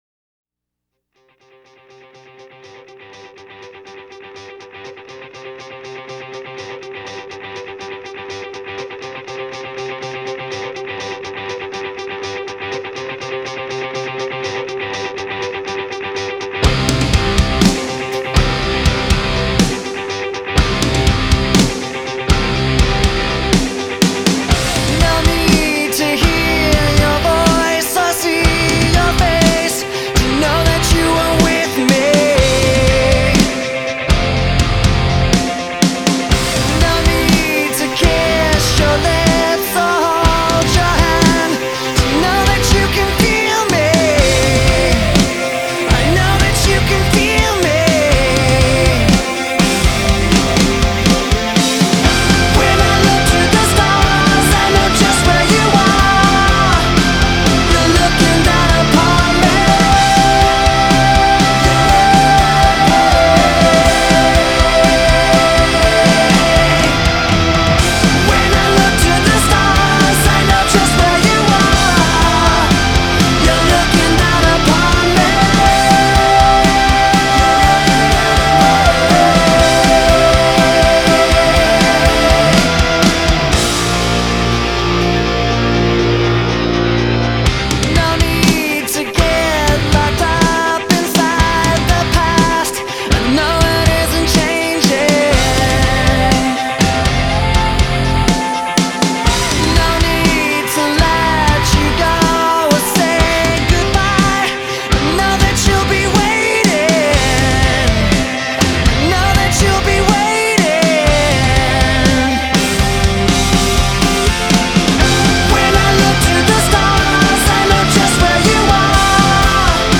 Genre: alternative rock,hard rock,rock